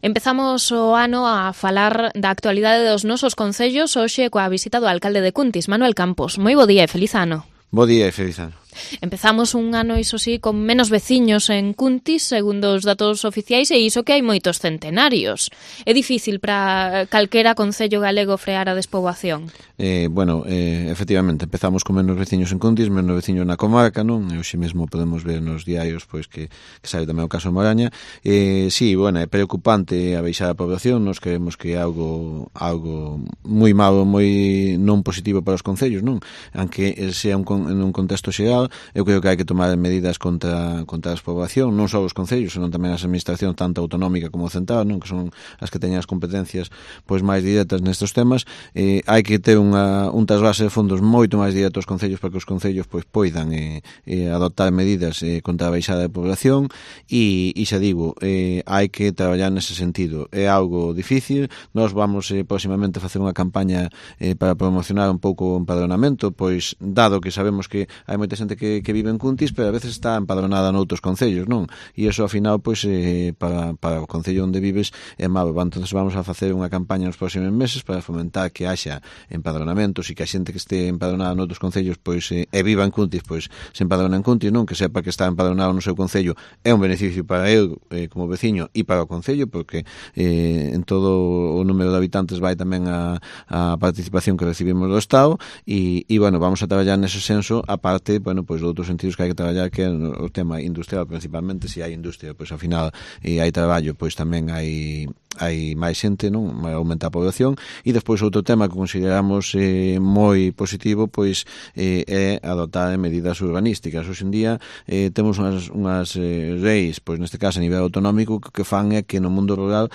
Entrevista a Manuel Campos, alcalde de Cuntis